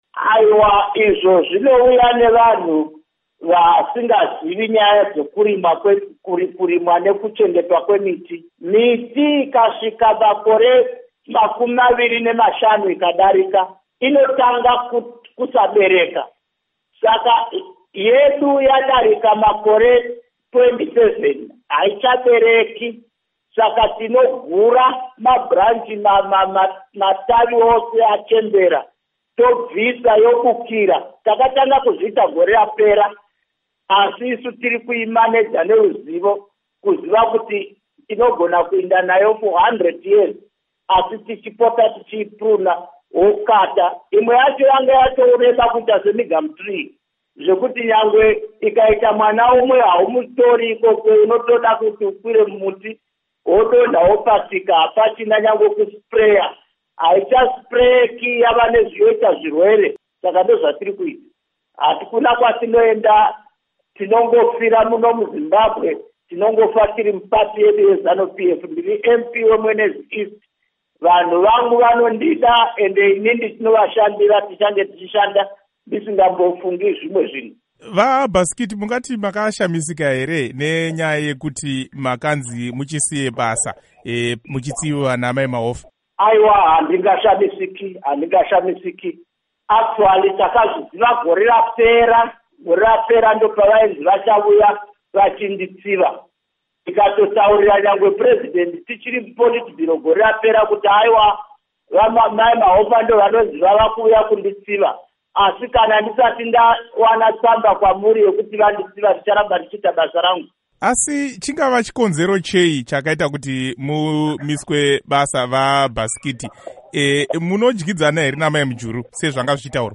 Hurukuro naVaKudakwashe Bhasikiti